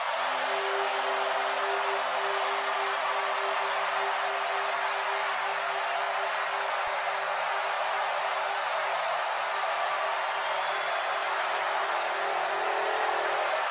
房屋下线Fx
描述：一个基本的白噪声过滤器。
Tag: 128 bpm House Loops Fx Loops 2.53 MB wav Key : Unknown